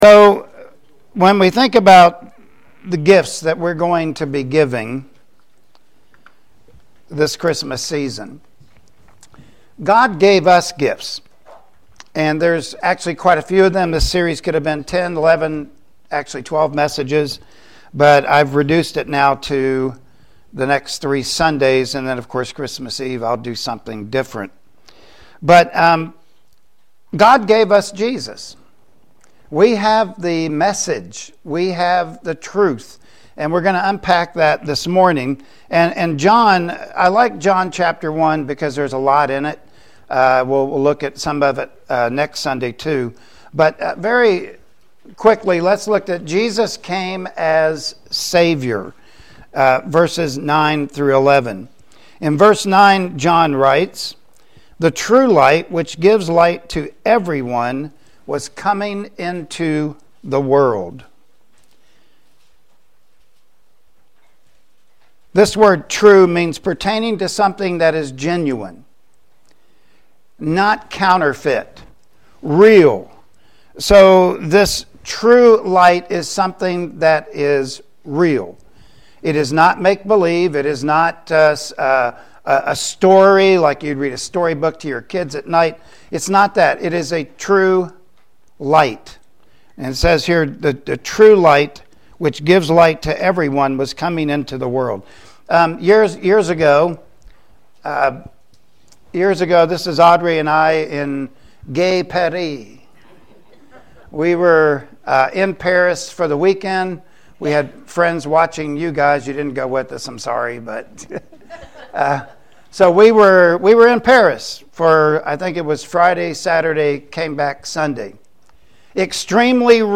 Service Type: Sunday Morning Worship Service Topics: Jesus is Savior